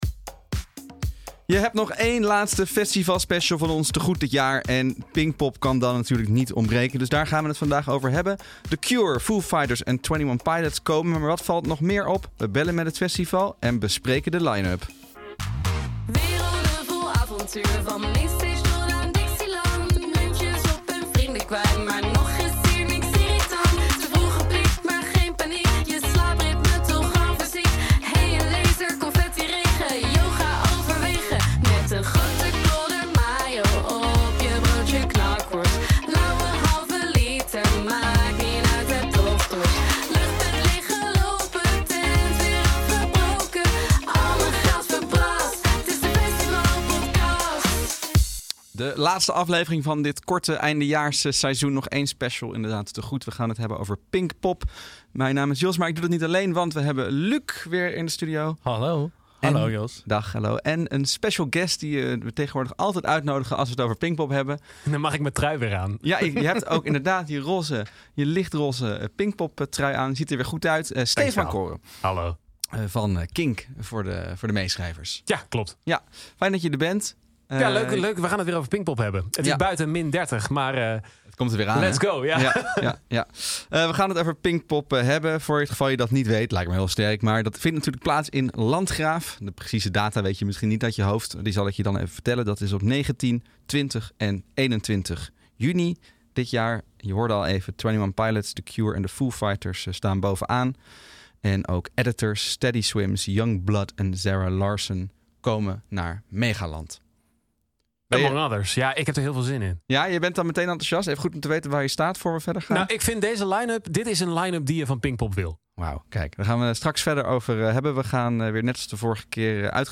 In de laatste Festivalpodcast van het jaar staat Pinkpop centraal. We bellen met de organisatie en leren van hen dat het festival meer wilt inzetten op weekend bezoekers, het comfort nog meer centraal wil stellen én hoe blij ze wel niet waren met de bevestiging van Foo Fighters. Ook zelf duiken we nog even de line-up in en analyseren de huidige koers van Pinkpop.